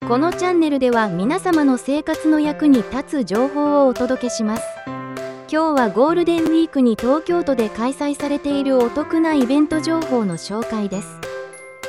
現在は11パターンのBGMが用意されています。どれもボイスが長くなっても綺麗にループするタイプのBGMになっていてバックグラウンドミュージックとして適切です。
BGM付きの音声がこちら
音声の後ろにBGMをつけるだけで一気に本格的になりましたね
リカ女性13.mp3